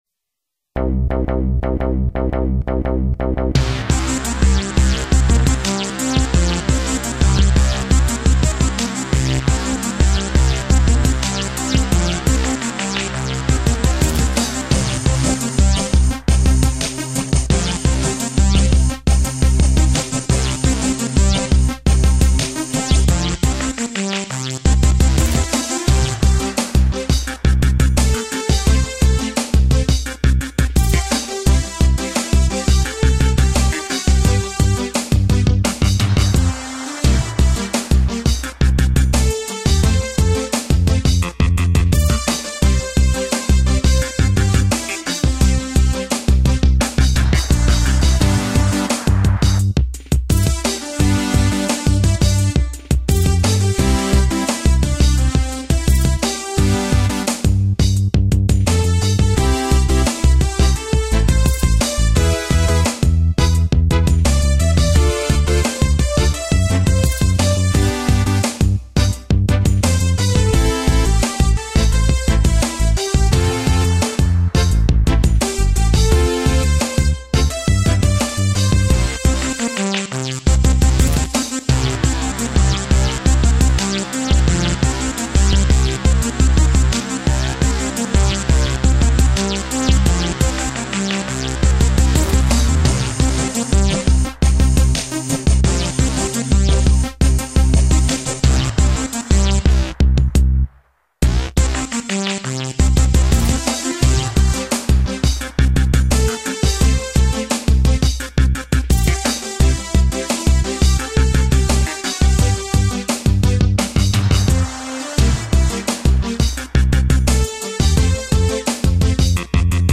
６．ダンスバージョン